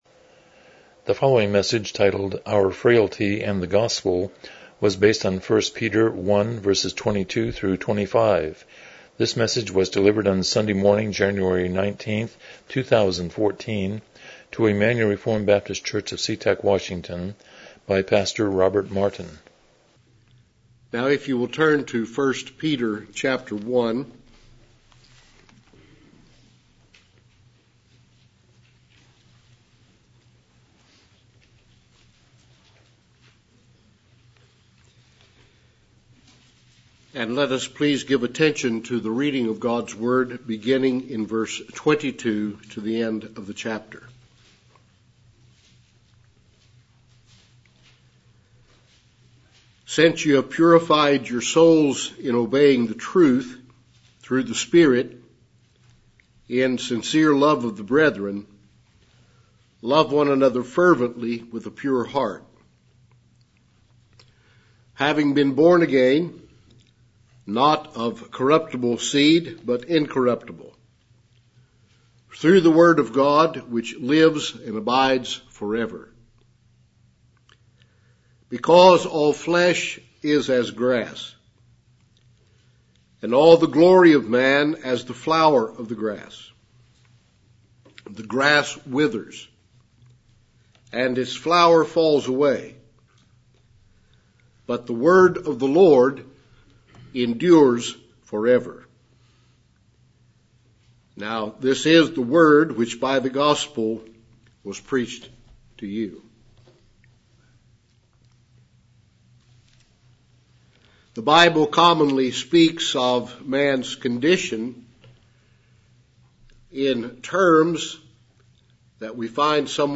Miscellaneous Passage: 1 Peter 1:22-25 Service Type: Morning Worship « 27 The Sovereignty of God